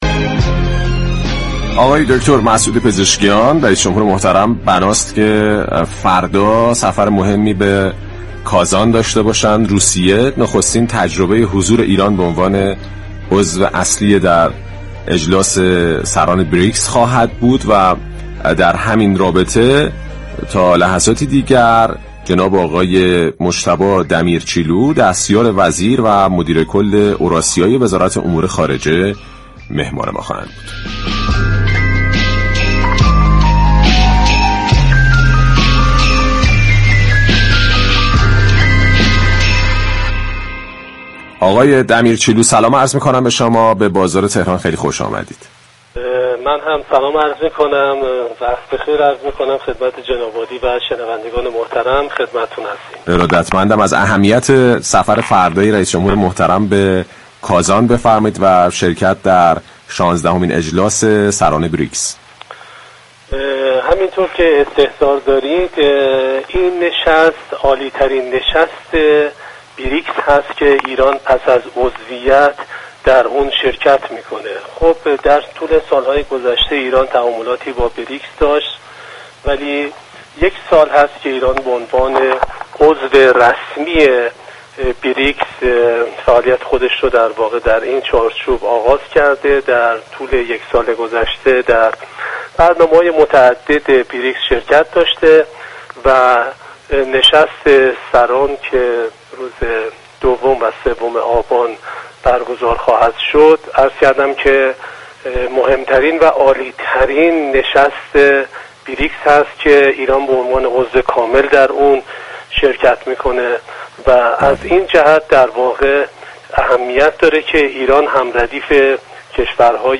دریافت فایل به گزارش پایگاه اطلاع رسانی رادیو تهران، مجتبی دمیرچی‌لو دستیار وزیر و مدیركل اورآسیای وزارت امور خارجه در گفت و گو با «بازار تهران» اظهار داشت: مسعود پزشكیان رئیس جمهور به دعوت ولادیمیر پوتین و برای شركت در شانزدهمین نشست سران بریكس، سه‌شنبه عازم شهر كازان محل برگزاری این اجلاس در روسیه، خواهد شد.